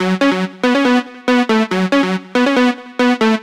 TSNRG2 Lead 028.wav